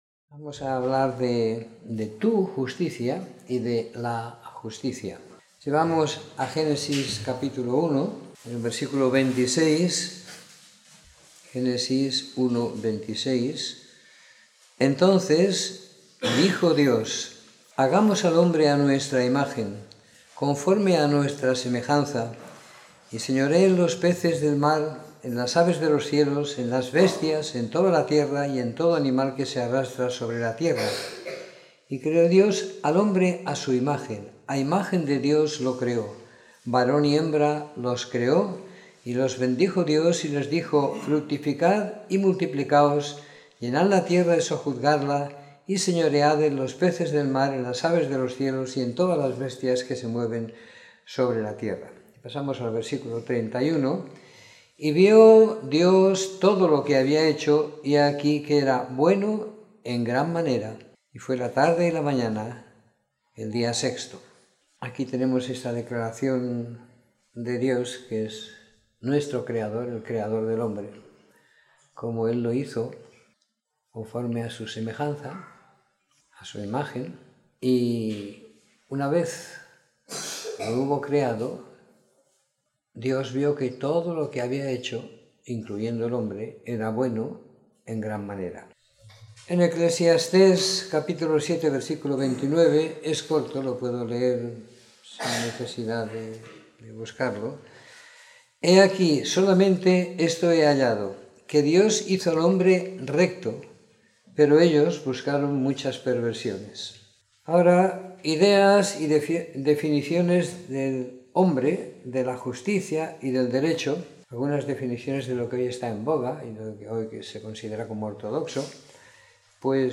Domingo por la Mañana . 24 de Septiembre de 2017